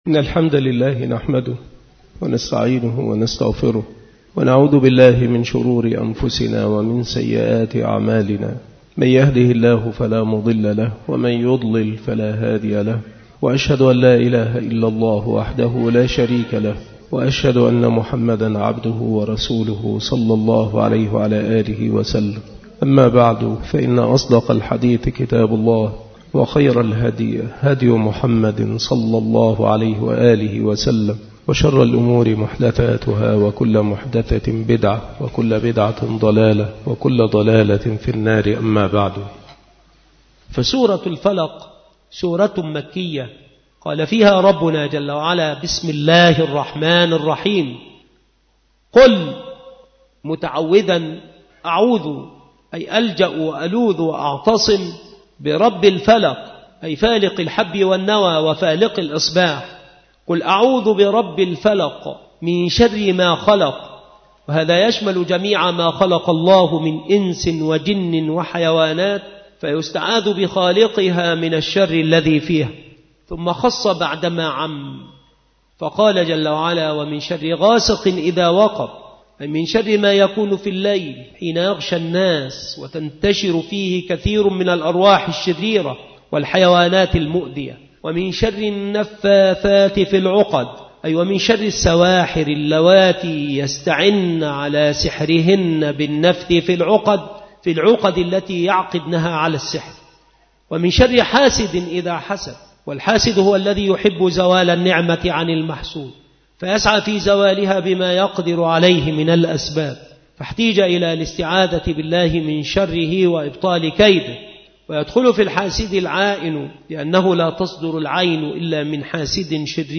• مكان إلقاء هذه المحاضرة : بالمسجد الشرقي بسبك الأحد - أشمون - محافظة المنوفية - مصر